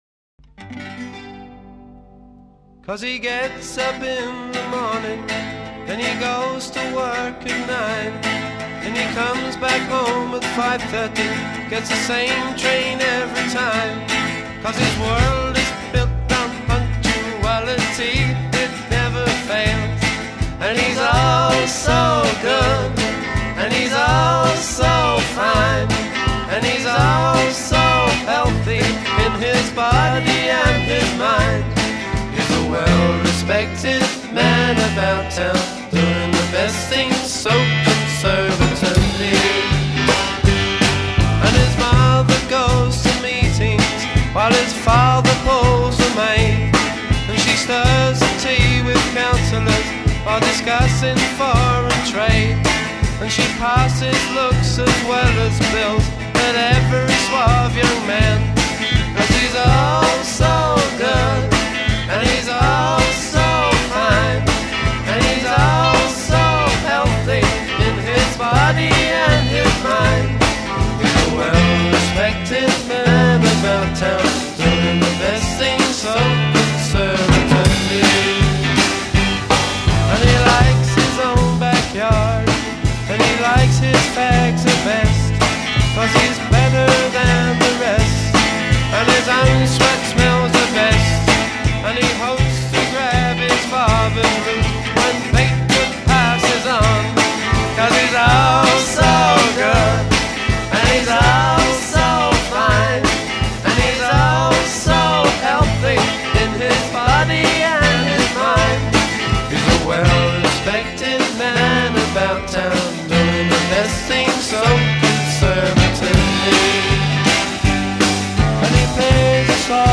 Intro 0:00 guitar strum (on dominant)
verse : 12 Solo vocal with ensemble. c